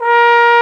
Index of /90_sSampleCDs/Roland LCDP12 Solo Brass/BRS_Trombone/BRS_Tenor Bone 4
BRS BONE  0C.wav